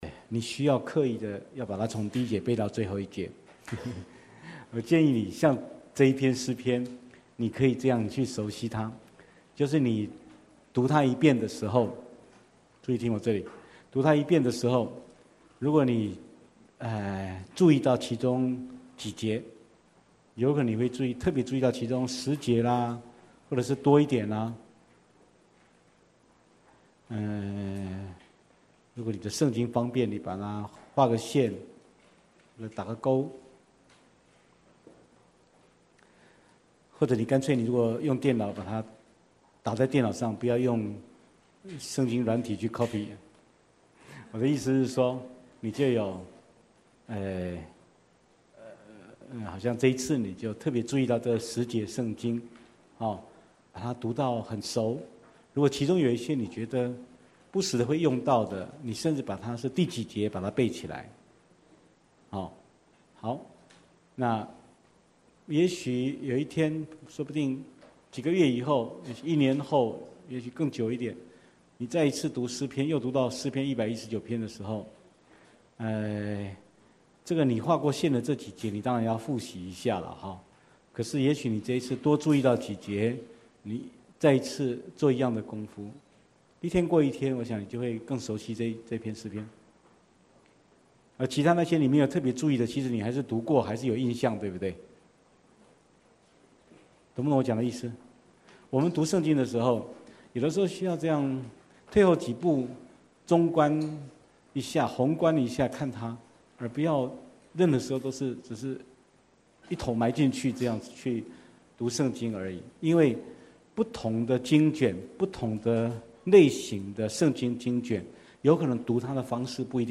本系列講於台中旌旗教會